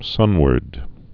(sŭnwərd)